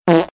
効果音 屁
効果音 屁 (着信音無料)
fart.mp3